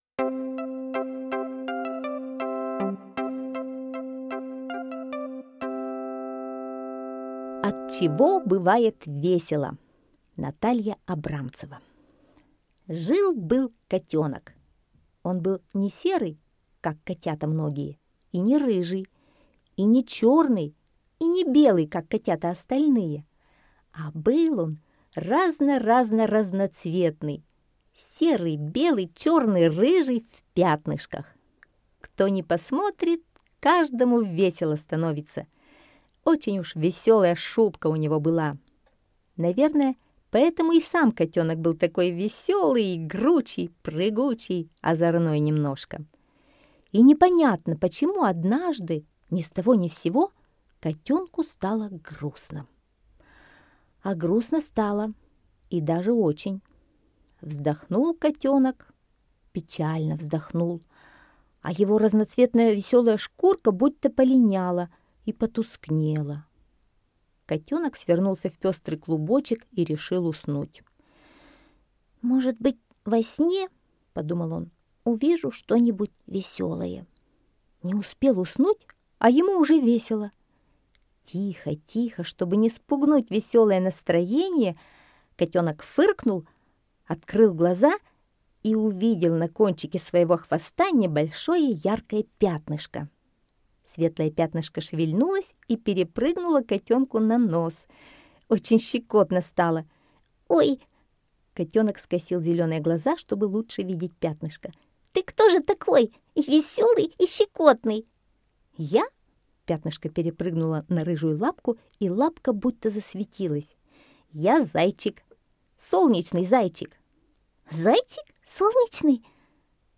От чего бывает весело - аудиосказка Абрамцевой Н. Сказка про котенка, шубка которого была окрашена в разноцветные пятнышки.